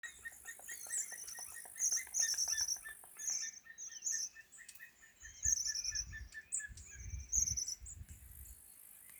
Birds -> Birds of prey ->
White-tailed Eagle, Haliaeetus albicilla